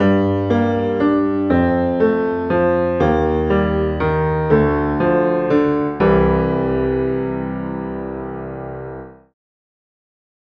Sintonía breve interpretada al piano
piano
melodía
sintonía
Sonidos: Música